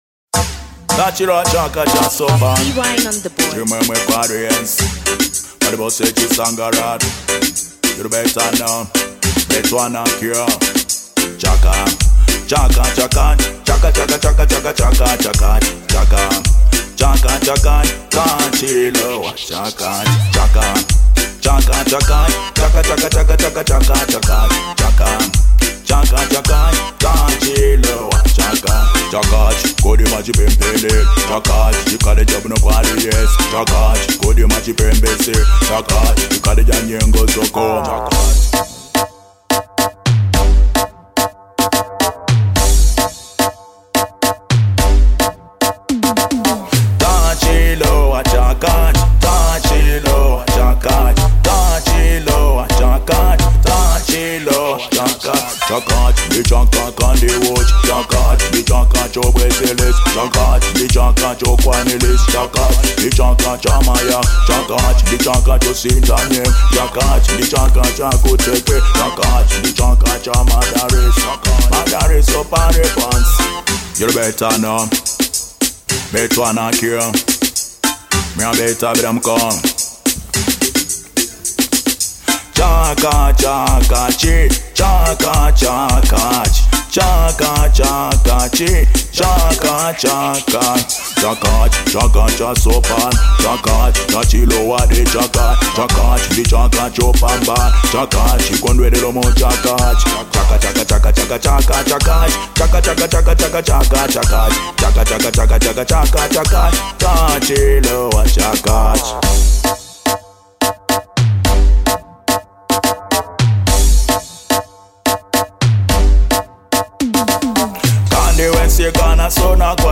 Genre : Ragga